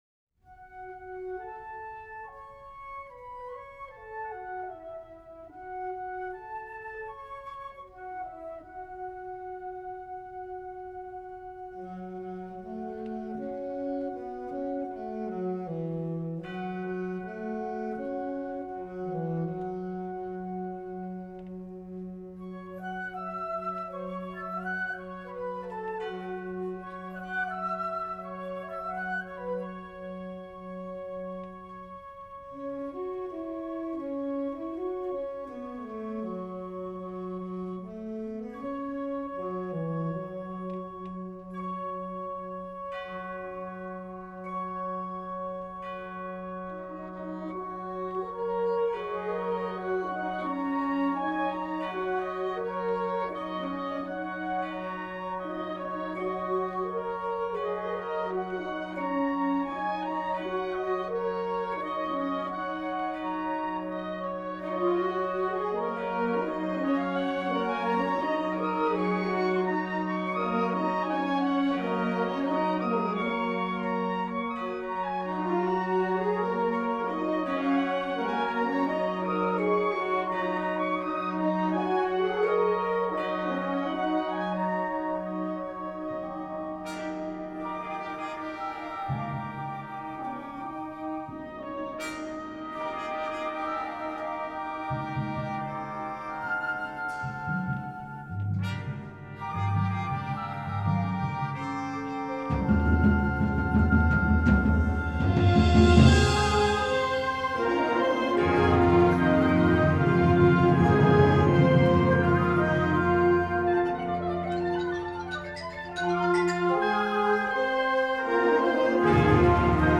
Nazareth College Wind Symphony
Live recordings